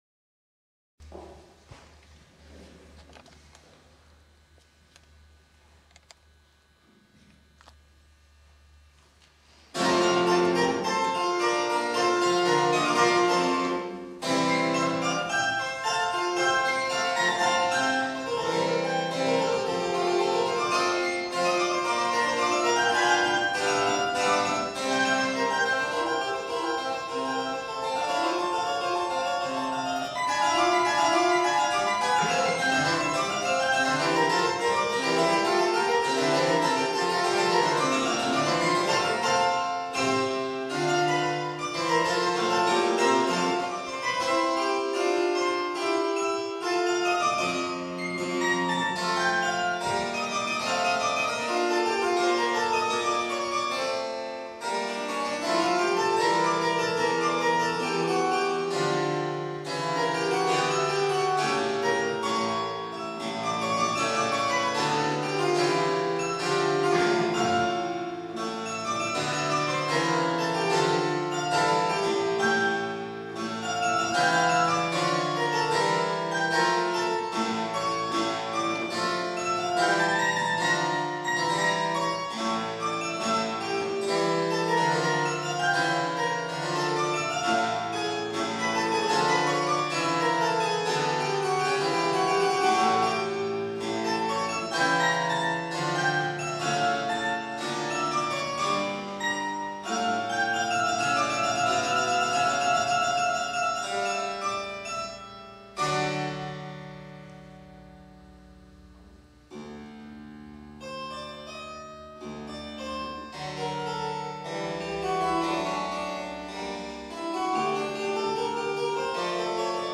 Cembalokonzerte
am 20.06.2025 im Rahmen der 600-Jahr-Feier der Kirche und der 700-Jahr-Feier des Dorfes Lanz (Prigniz)
... eine Hörprobe am Cembalo -